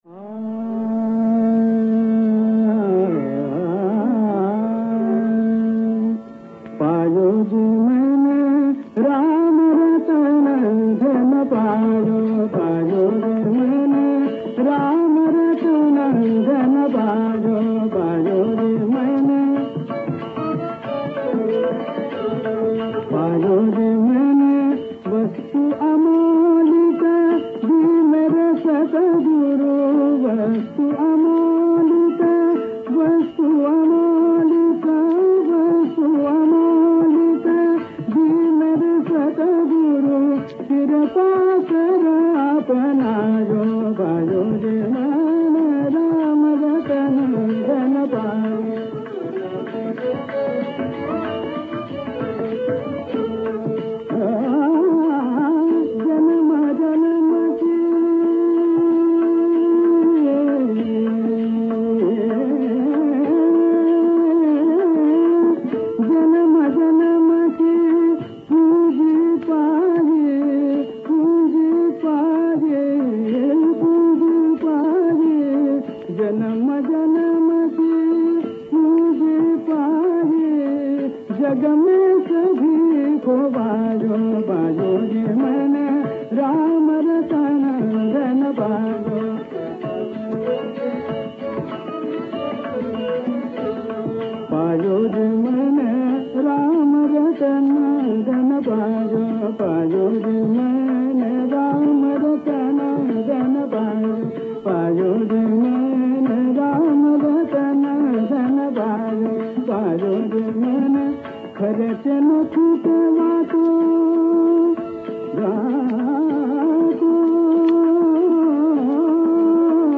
Raga Pahadi
A confluence of Pahadi, Kalyan and Mand is seen in this universally loved Meera bhajan by D.V. Paluskar: